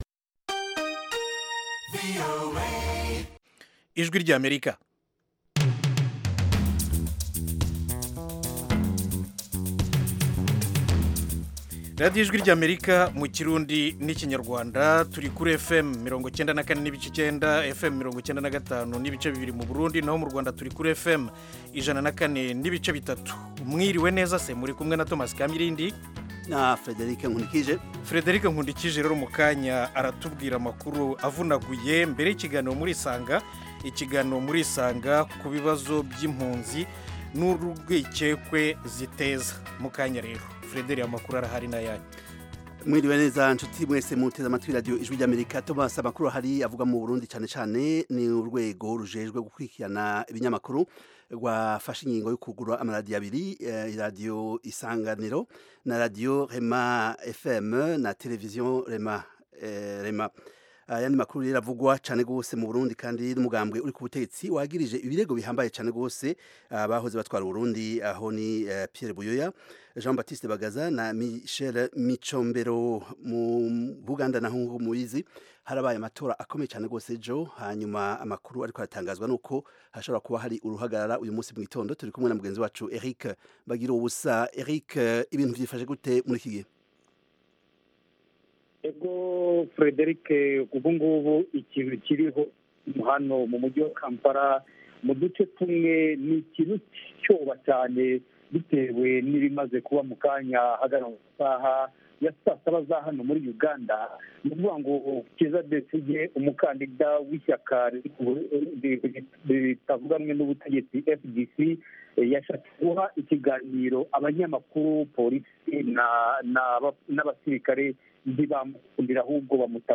Murisanga itumira umutumirwa, cyangwa abatumirwa kugirango baganira n'abakunzi ba Radiyo Ijwi ry'Amerika. Aha duha ijambo abantu bivufa kuganira n'abatumirwa bacu, batanga ibisobanuro ku bibazo binyuranye bireba ubuzima b'abantu.